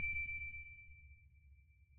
sonarTailSuitFar2.ogg